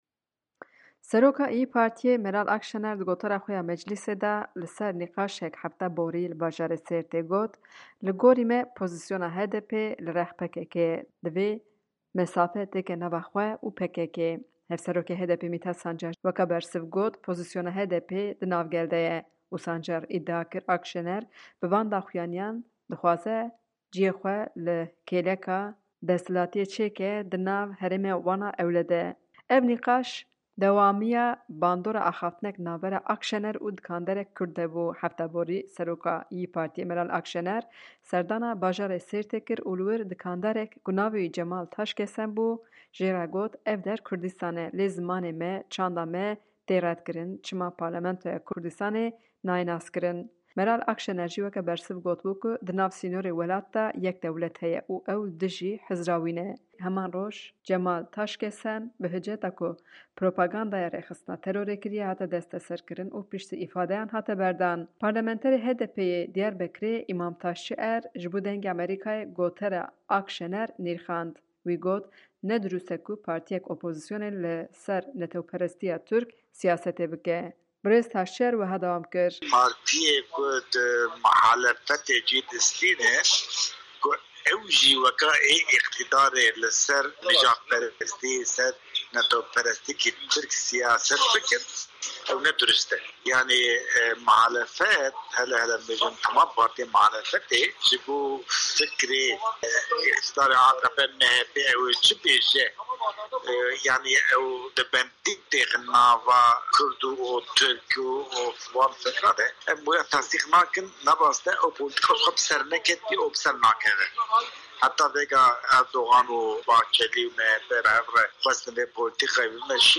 Parlamenterê HDP’ê yê Amedê Îmam Taşçier ji Dengê Amerîka re got ku ne ehlaqî ye ku partîyên opozîsyonê li ser neteweperestîya Tirk sîyasetê bikin.